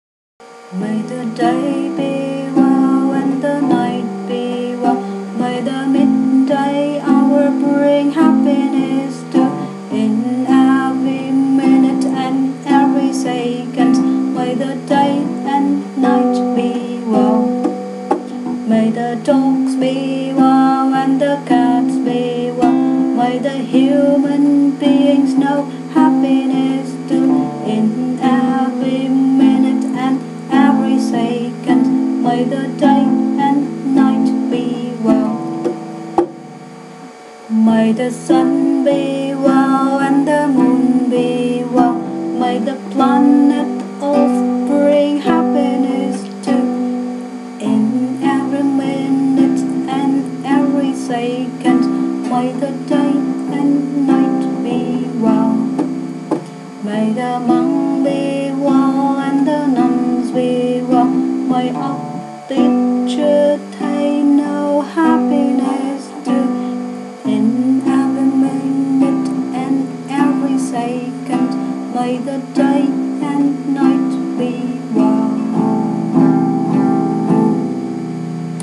Có thể kết hợp hát nhóm với tiếng vỗ tay sau mỗi đoạn.